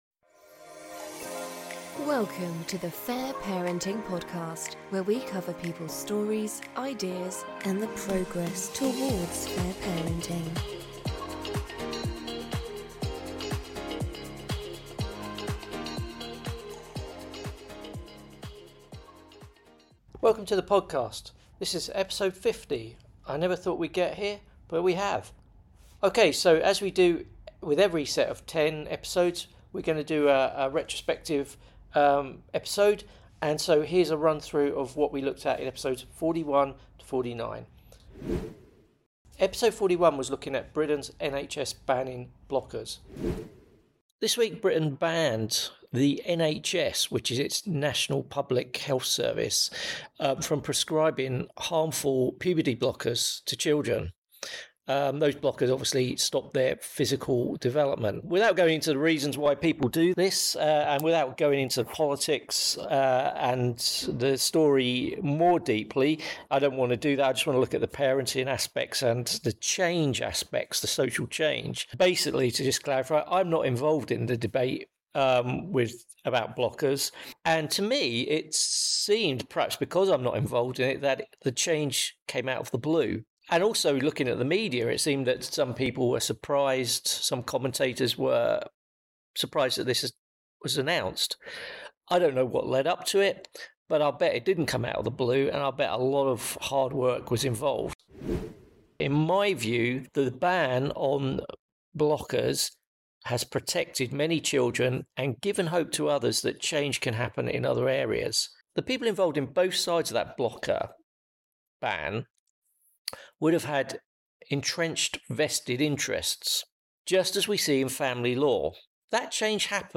This episode is a compilation of episode clips rather than a ‘best of’ clips as that is individual choice.